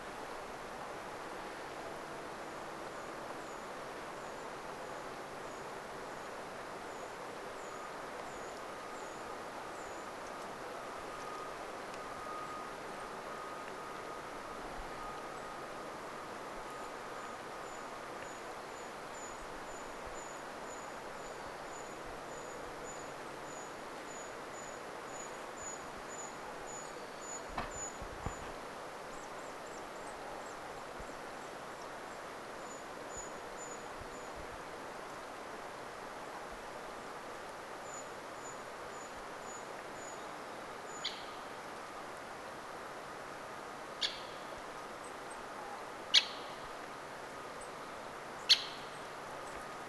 Kävelemässä luontopolulla.
Se puukiipijälintukin äänteli hetken kuluttua
vähän taigauunilintumaisesti.
puukiipijän taigauunilintumaista ääntelyä
ehka_kuvan_puukiipijalinnun_hieman_taigauunilintumaista_aantelya.mp3